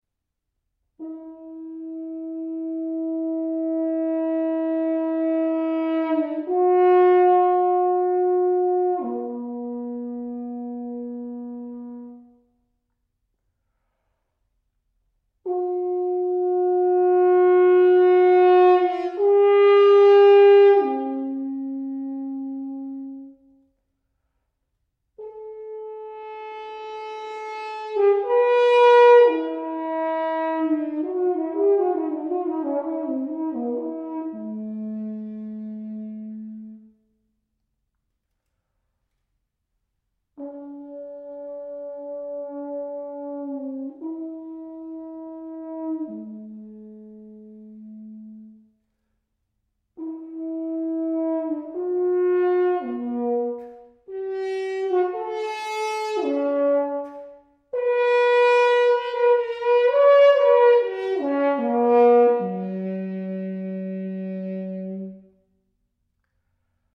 UK based natural hornist